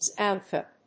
音節Xan・thip・pe 発音記号・読み方
/zæntípi(米国英語)/